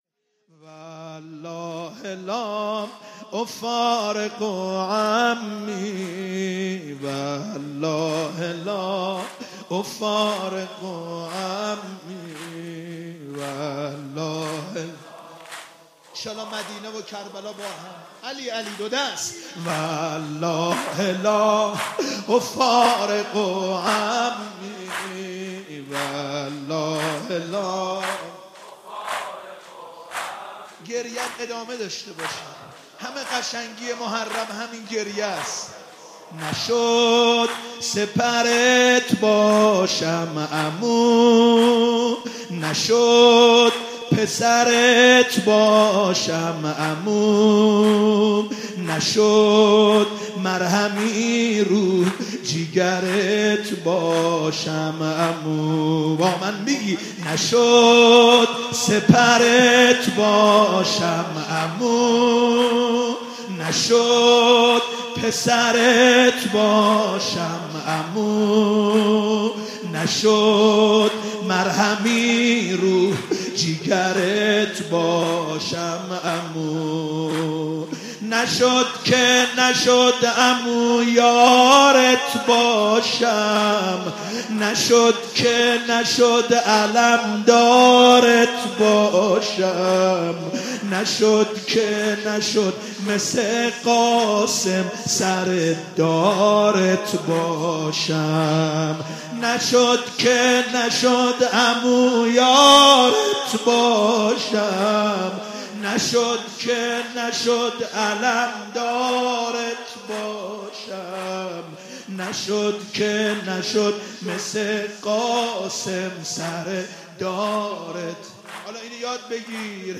در مراسمی که به مناسبت شب پنجم محرم در هیئت رزمندگان اسلام برگزار شد
مداحی و مرثیه خوانی